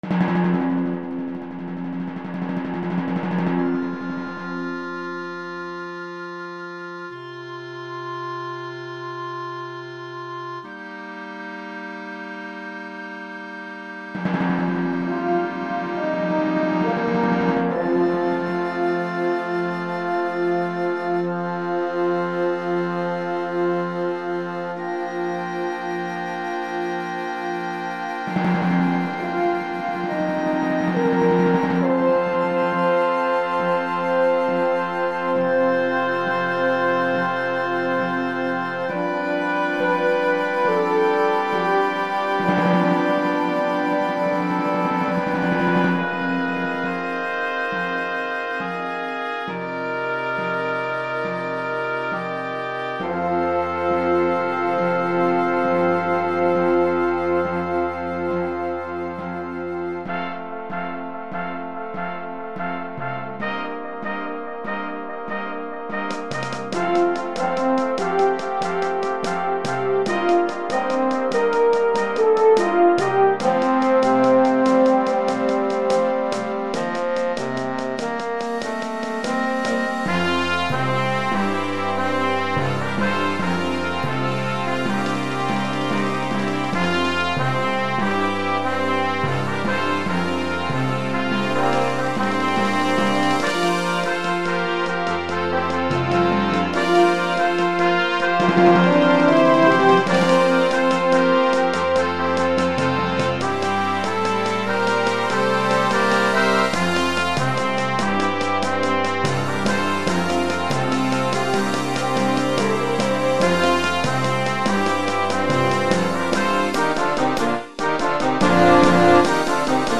here is a synthesized version